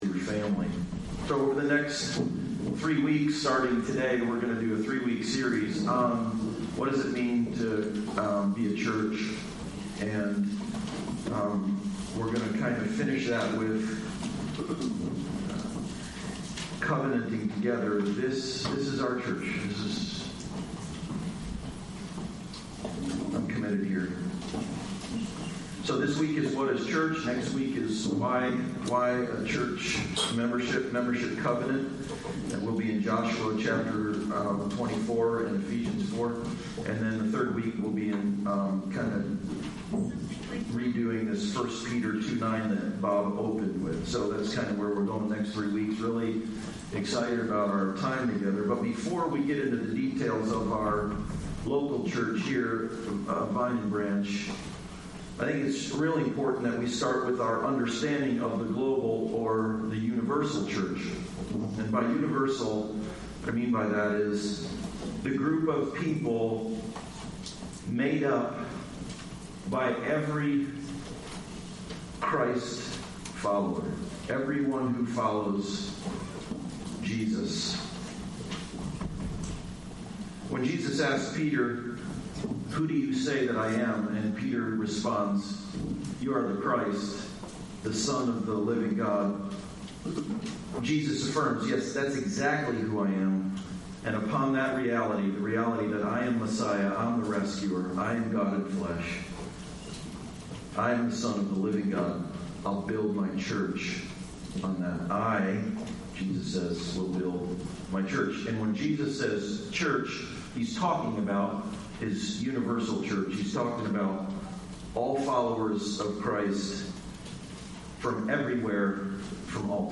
Membership Passage: 1 Corinthians 12:4-31 Service Type: Sunday Service Topics